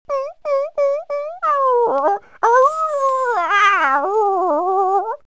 Dog Yelp Sound Button - Free Download & Play